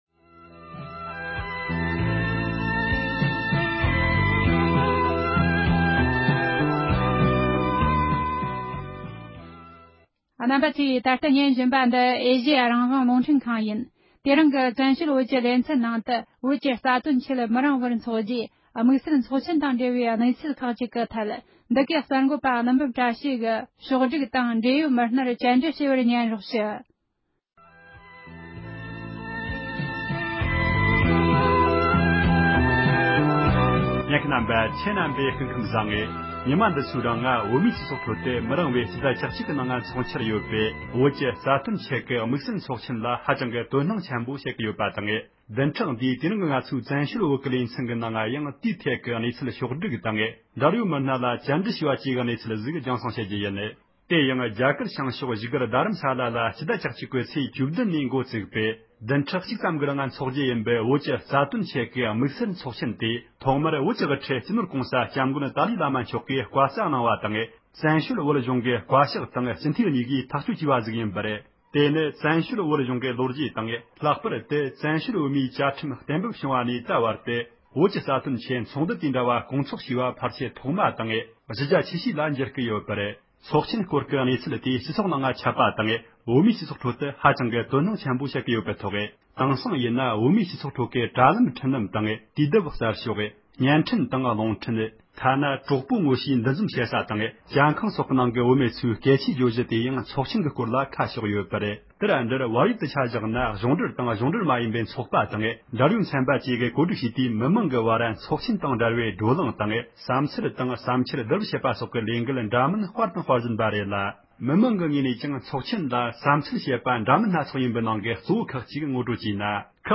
སྒྲ་ལྡན་གསར་འགྱུར། སྒྲ་ཕབ་ལེན།
འབྲེལ་ཡོད་མི་སྣར་བཀའ་འདྲི་ཞུས་པ་ཞིག་གསན་རོགས་གནང་༎